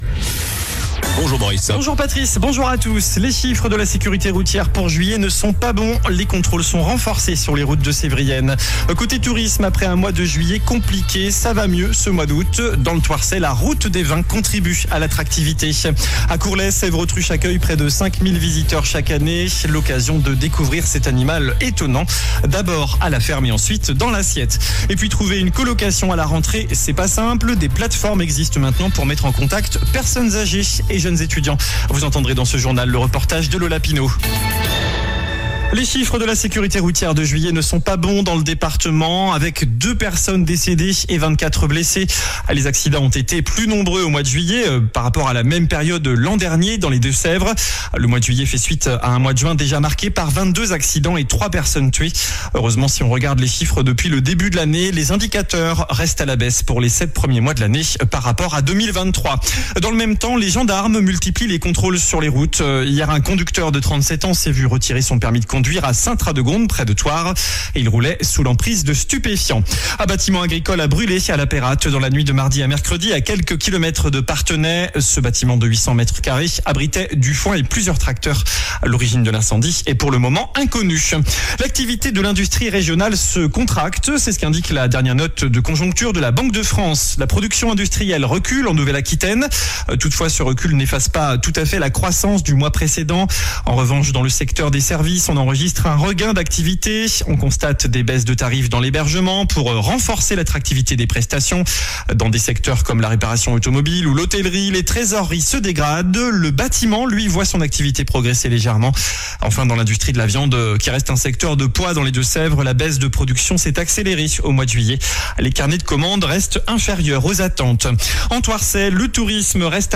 JOURNAL DU VENDREDI 16 AOÛT ( MIDI )